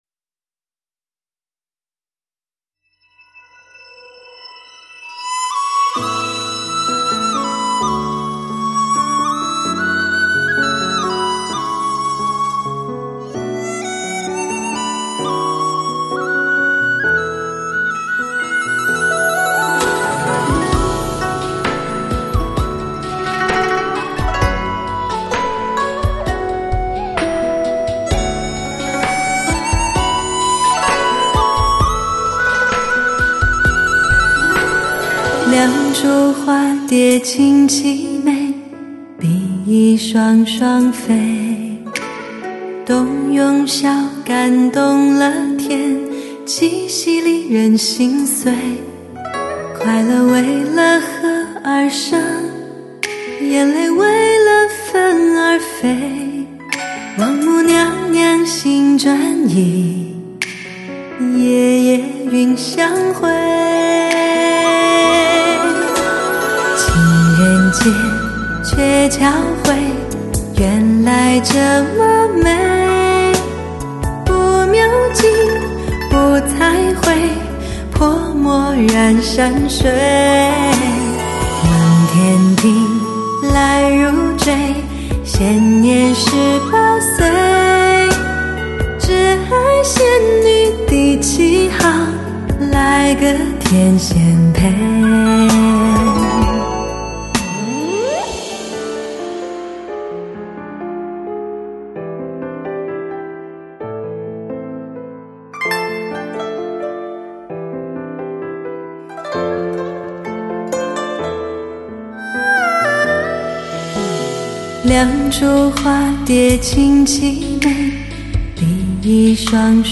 音质上也进行了提升，势必成为广大发烧友购买器材时，必备的检测利器！